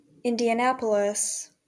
Indianapolis (/ˌɪndiəˈnæpəlɪs/
IN-dee-ə-NAP-ə-lis),[10][11] colloquially known as Indy, is the capital and most populous city of the U.S. state of Indiana and the county seat of Marion County.